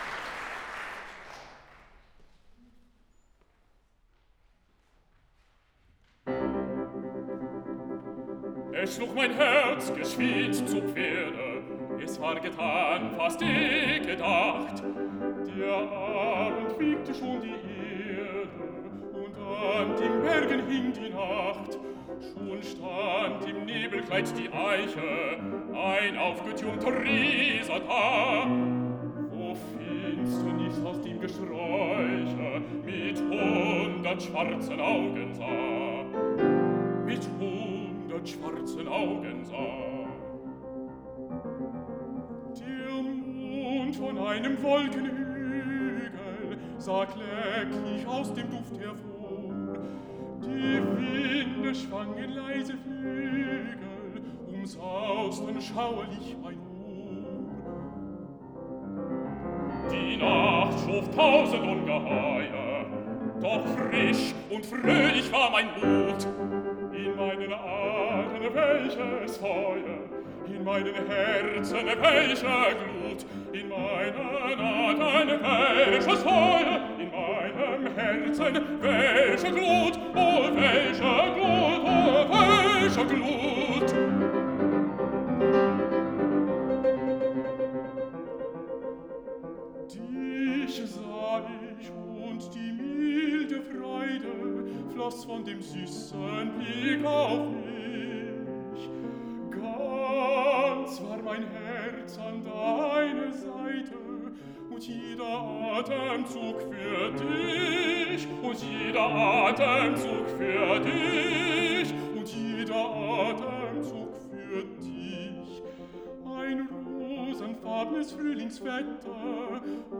Baritone
Piano
Live: Wigmore Hall International Song Competition
Willkommen-und-Abschied-Wigmore-Song.wav